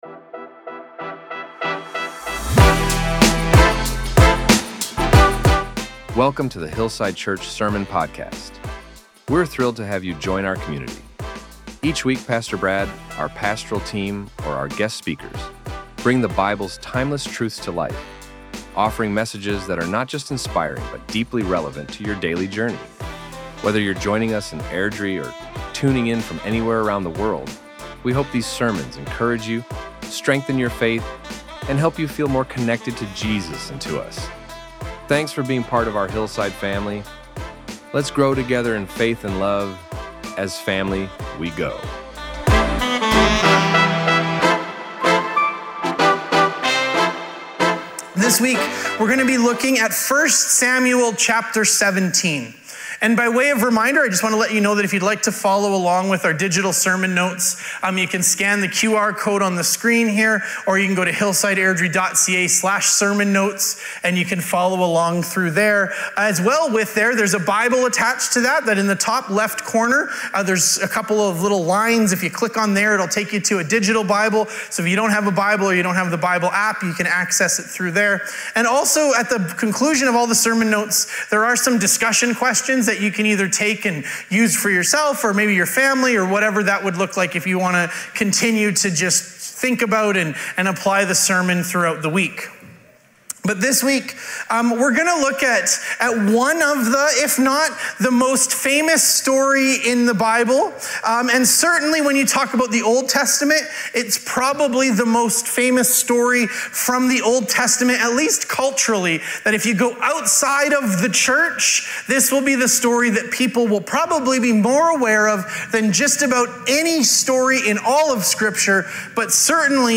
Our Sermons | HILLSIDE CHURCH